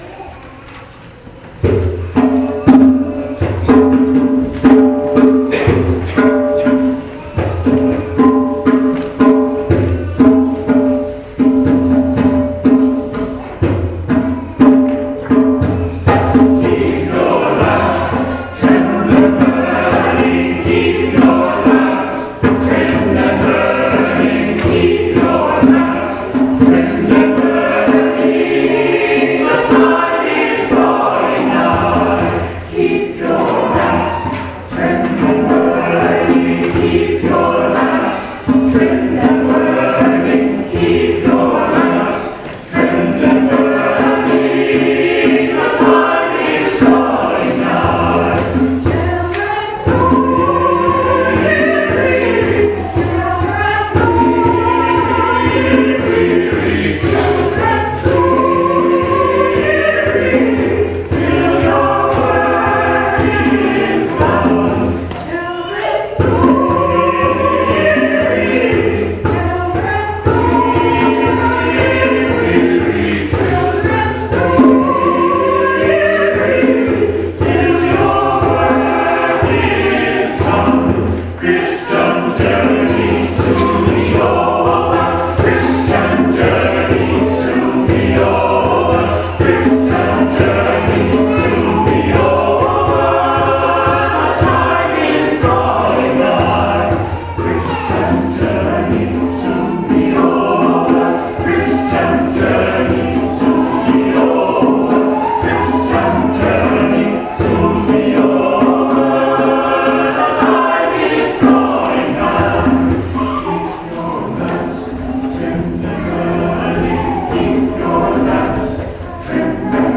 Keep Your Lamps Andre Thomas MCC Senior Choir Anthem March 15, 2015 Download file Keep Your Lamps